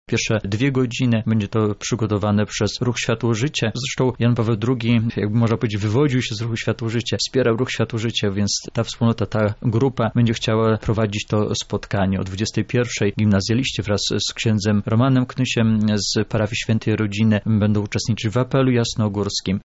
O szczegółach mówi ksiądz